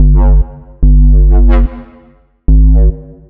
nice smooth womps.wav